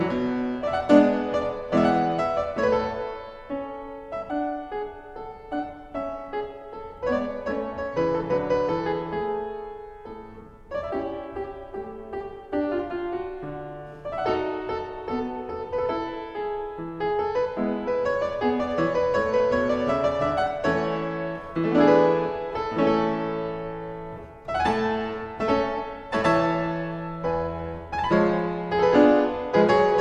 0 => "Musique de chambre"